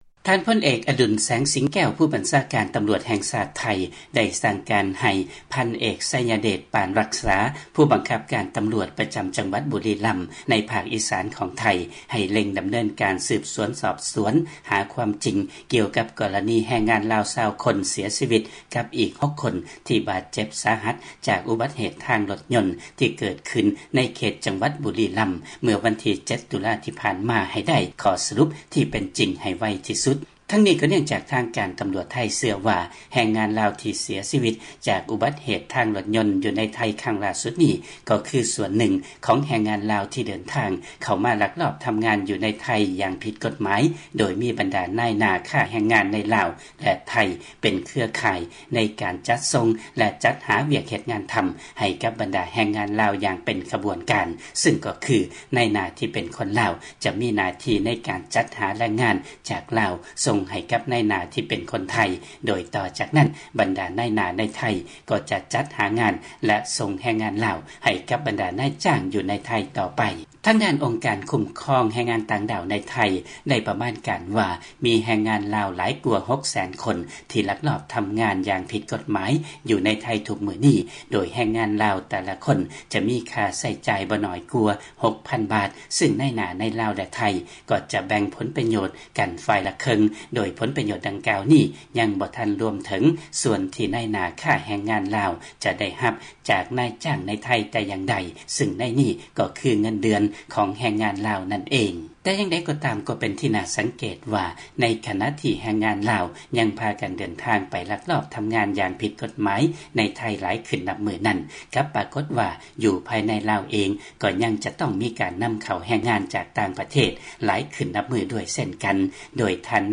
ຟັງລາຍງານກ່ຽວກັບ ຄົນງານລາວເສຍຊີວິດ ໃນໄທ